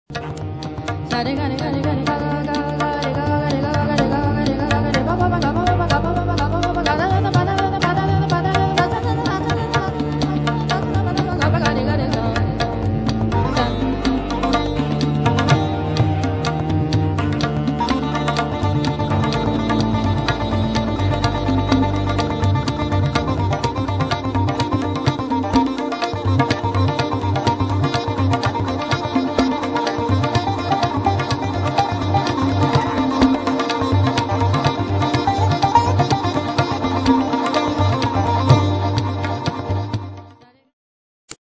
NuJazz & indische Musik in einer
konzertanten Begegnung
Sitar
Tabla & Percussion
Violoncello & Sampler & Live Elektronik
Indischer Gesang
Keyboards
NuJazz // Ethno Funk